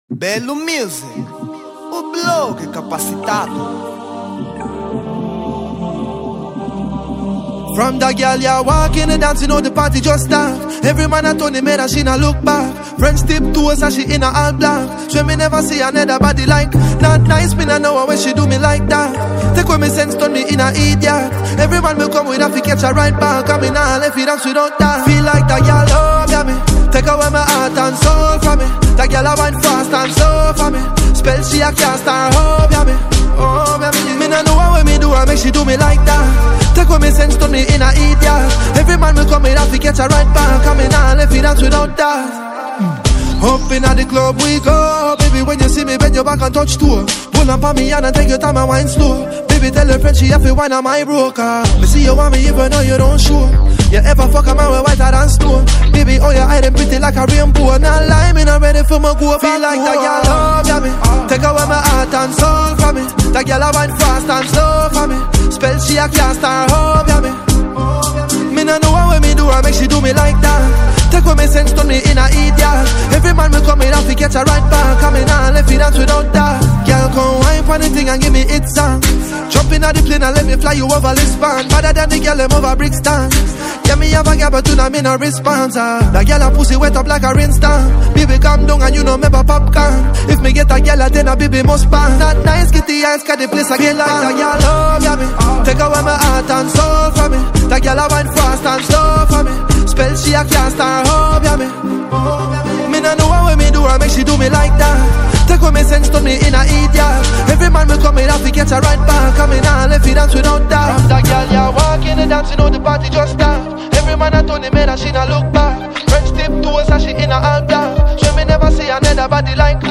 Género : Afro Vibe